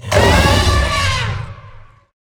combat / ENEMY / baurg / att2.wav